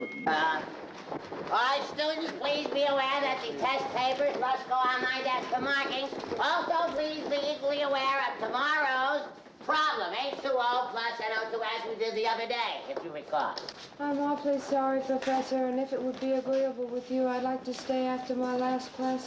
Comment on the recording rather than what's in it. On this page, I have only 5 sequence examples taken from actual movies.